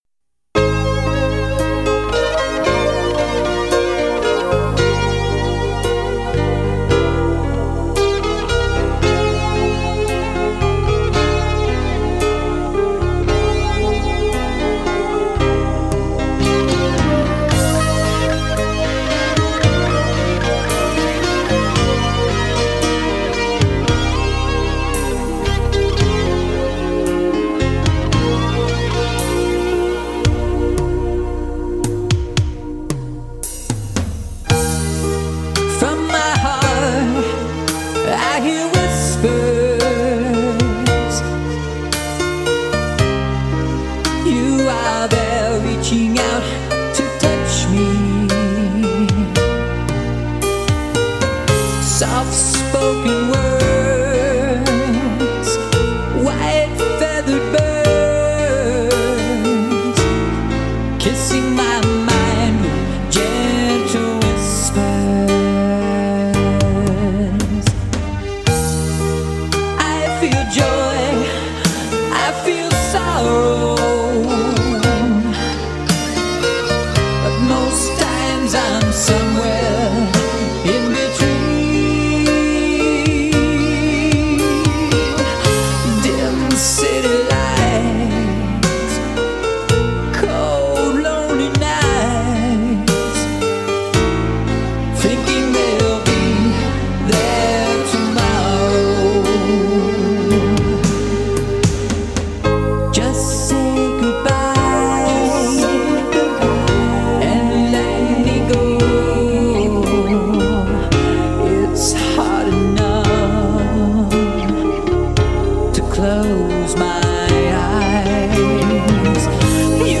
Recorded at Rabid Ears Studio - Lancaster, Pa.